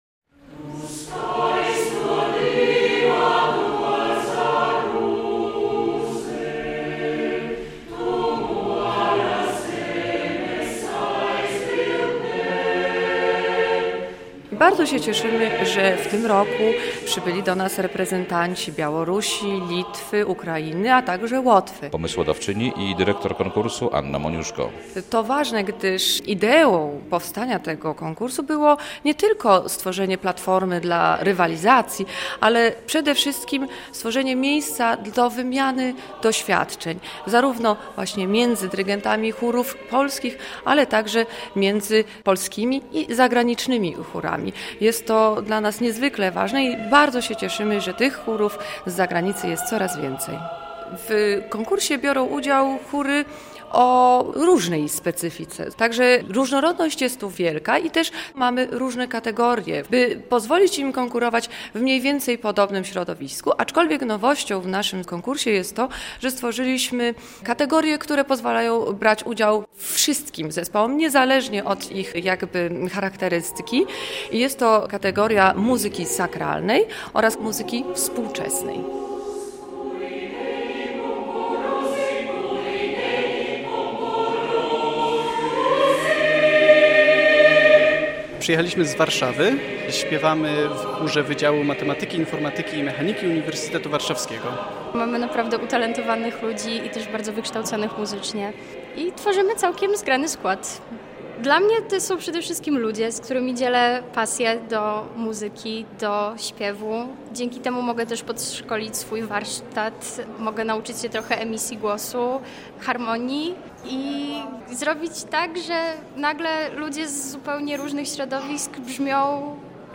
Konkurs "Cantu Gaudeamus" - muzyka chóralna rozbrzmiewa w Pałacu Branickich w Białymstoku